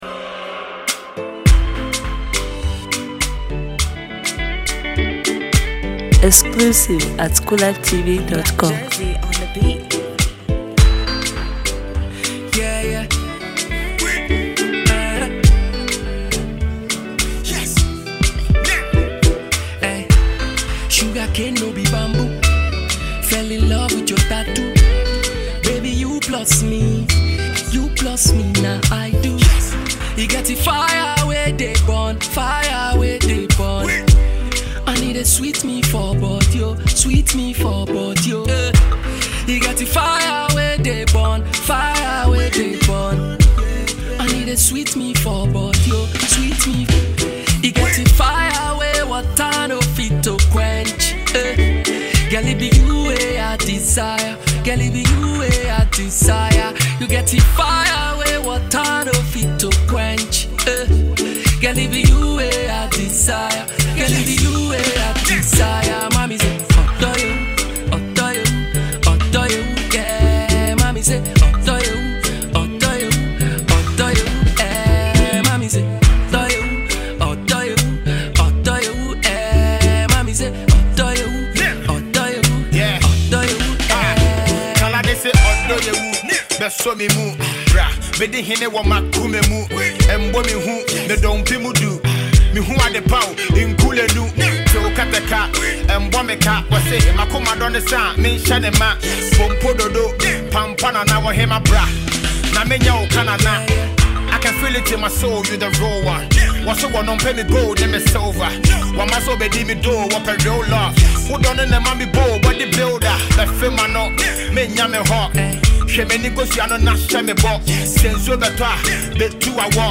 where he’s been joined by Ghanaian rap sensation